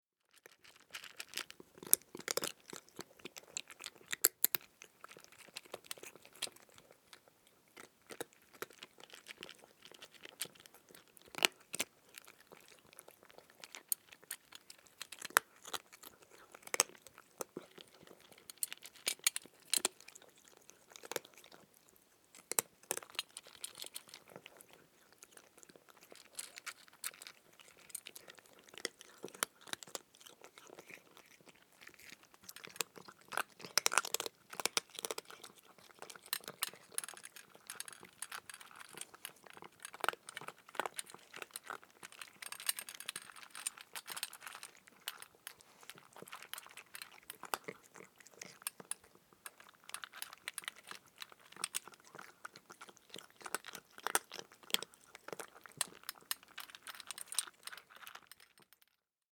Cat Eating Dry Food Bouton sonore